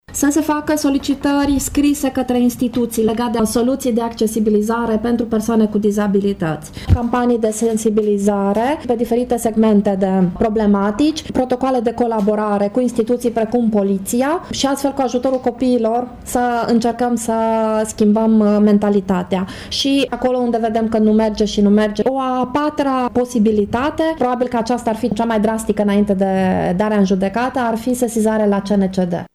Aceasta este concluzia mesei rotunde de astăzi de la sediul Fundației Alpha Transilvană din Tîrgu-Mureș, la care au participat reprezentanți ai ONG-urilor care au ca obiect drepturile persoanelor cu dizabilități și reprezentanți ai administrației locale.